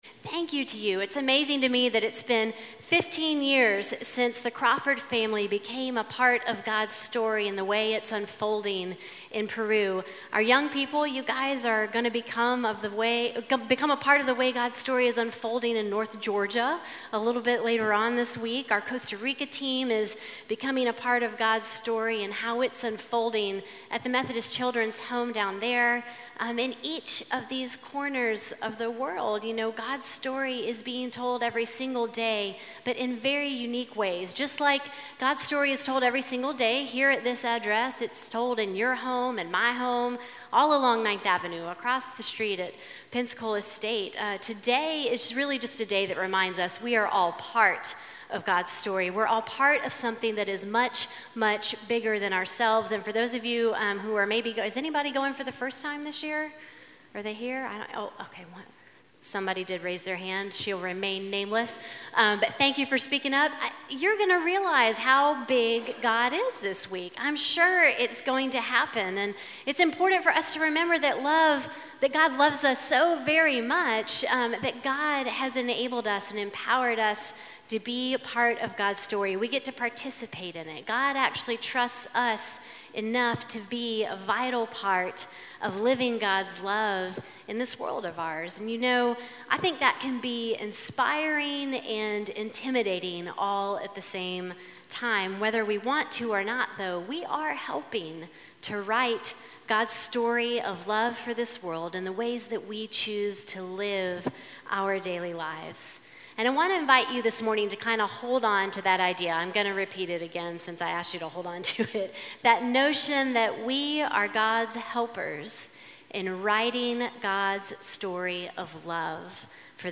A message from the series "10 Core Words."